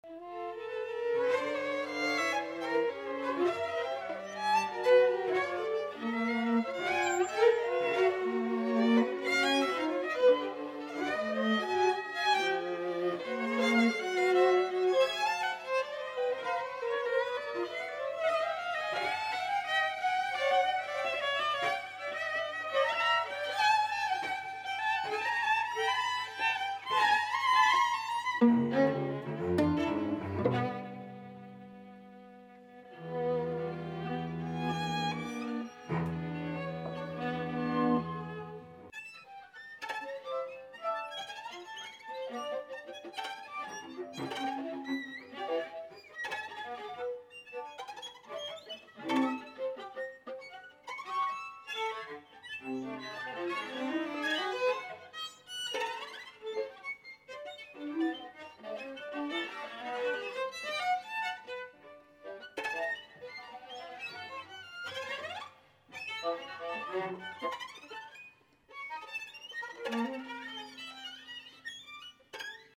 audio 44kz stereo